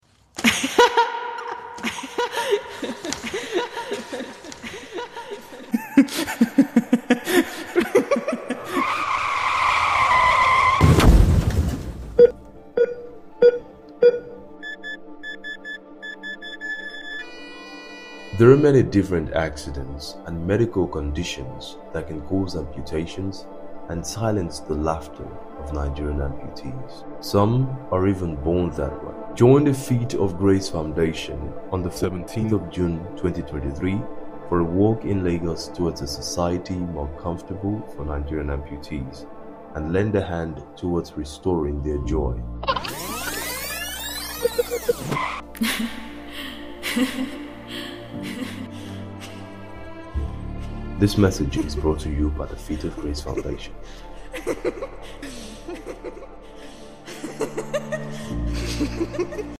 PLAY RADIO AD